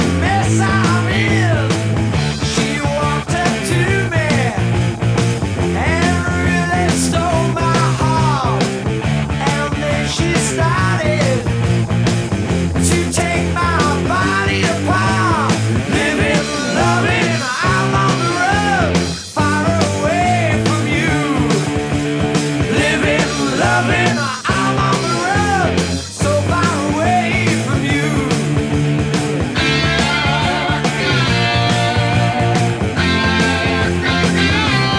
Жанр: Рок
Hard Rock